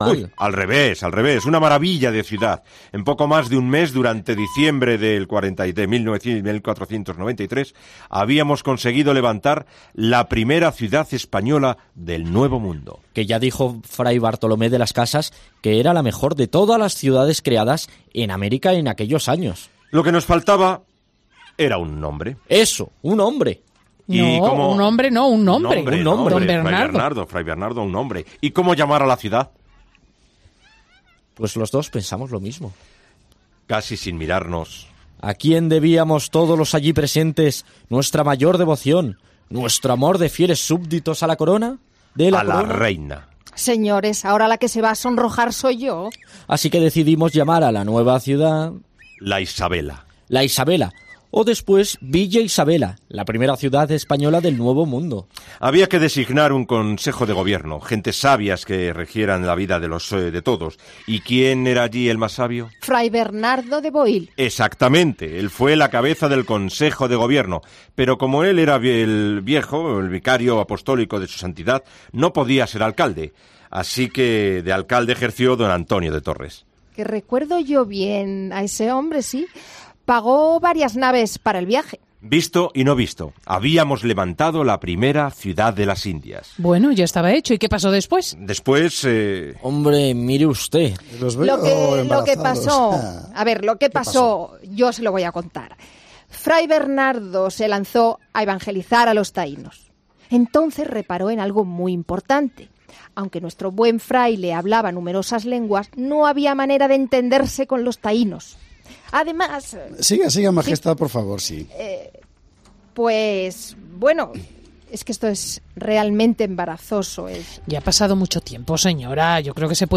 Escucha la recreación que el programa 'La Tarde' de COPE realizó el 28 de enero de 2010 sobre el momento histórico de la denominación de La Isabela